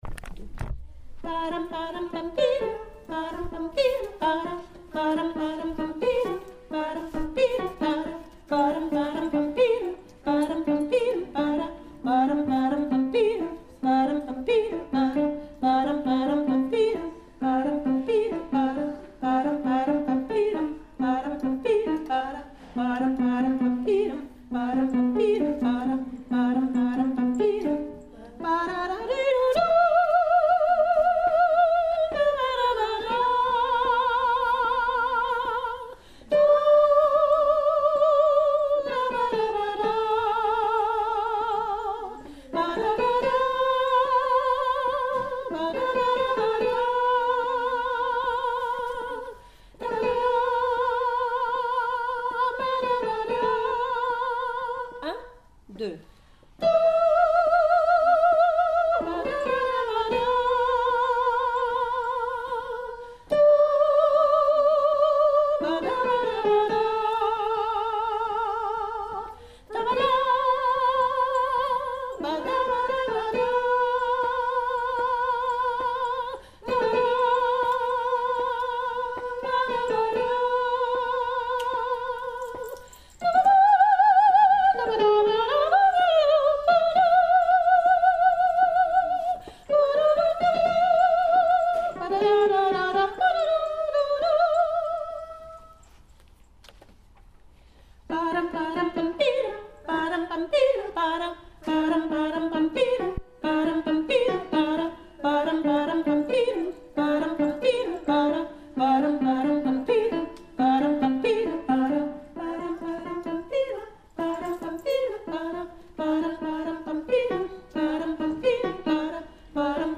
MP3 Libertango sop entier